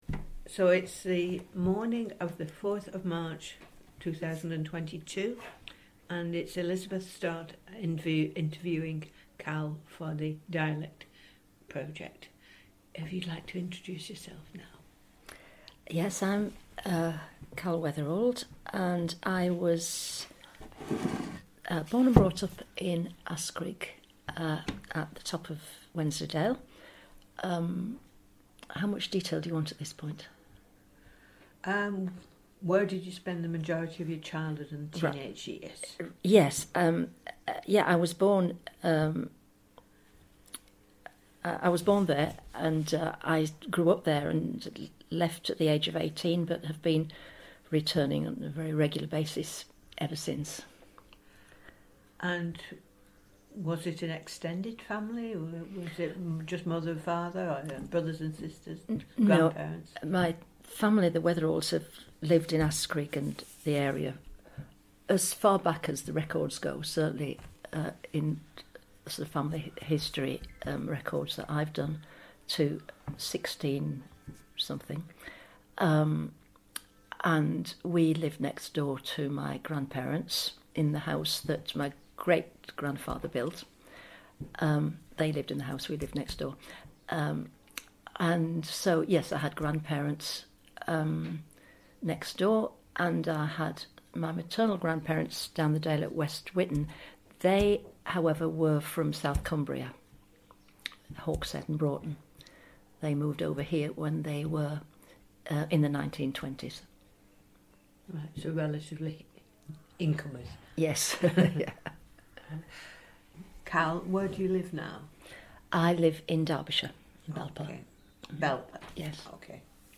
Digital recording of oral history interview conducted with named interviewee (see item title) as part of National Lottery Heritage funded, "Dialect and Heritage" Project.